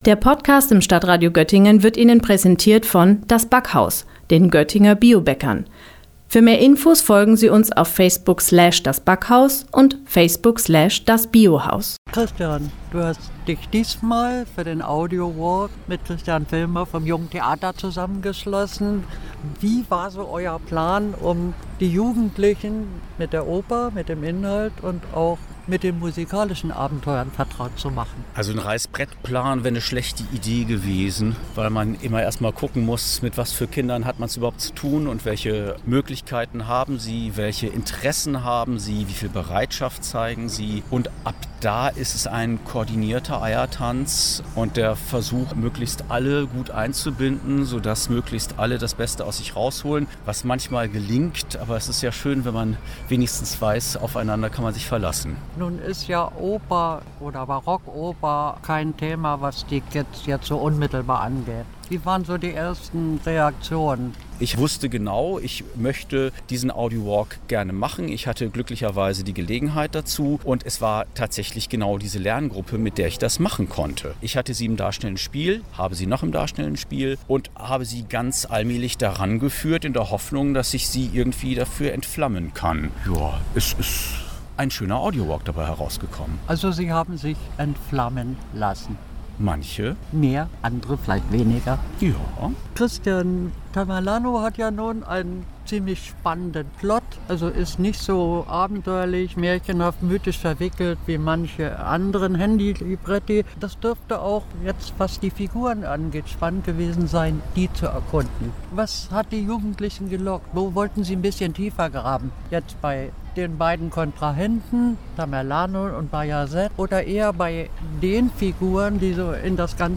Am Händel-Denkmal mit Blick auf das Deutsche Theater ging der Audio-Walk am Dienstag an den Start.